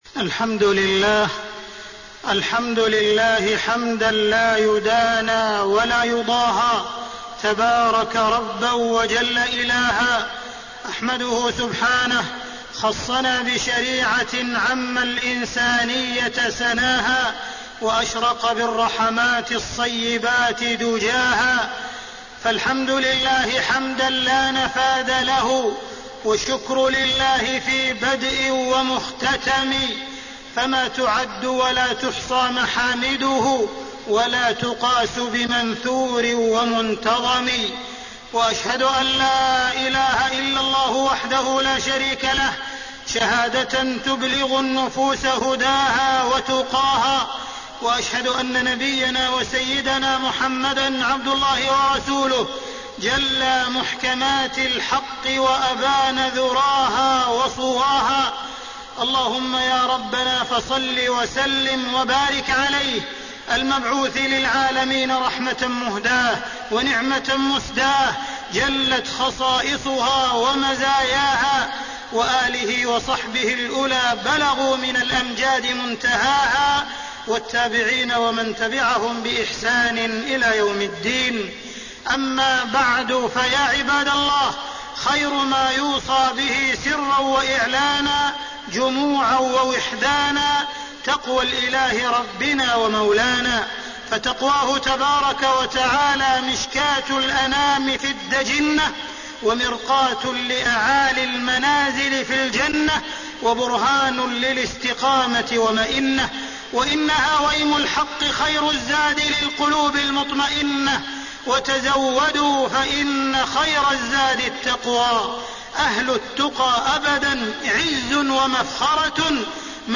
تاريخ النشر ١٩ ذو القعدة ١٤٣٣ هـ المكان: المسجد الحرام الشيخ: معالي الشيخ أ.د. عبدالرحمن بن عبدالعزيز السديس معالي الشيخ أ.د. عبدالرحمن بن عبدالعزيز السديس حماية جناب الإسلام